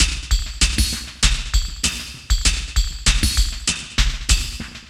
98WAGONLP1-L.wav